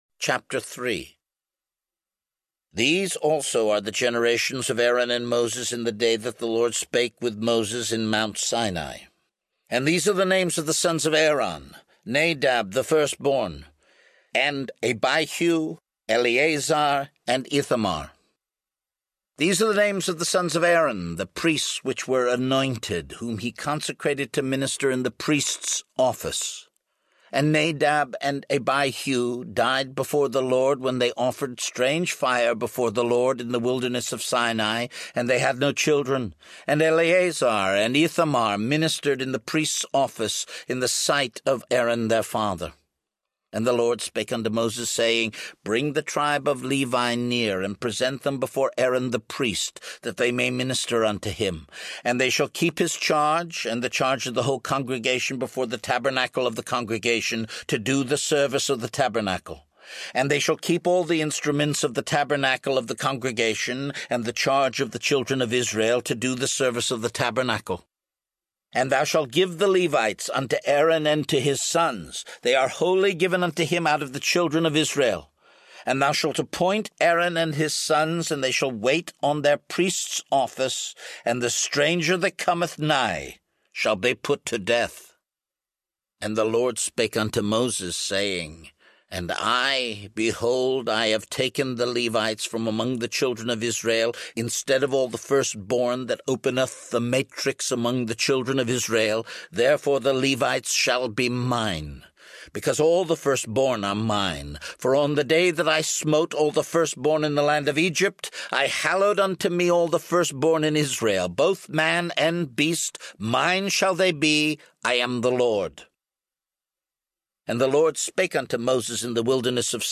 The King James Version (KJV) of Numbers in a pure voice audio format. Clear and uncluttered, this Bible speaks plainly, making it easier for you to enter into God’s Word. Actor and television director Kristoffer Tabori speaks new life to the cherished and revered translation.
3.4 Hrs. – Unabridged